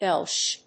読み方：エルゼットエッチ、エル・ゼット・エイチ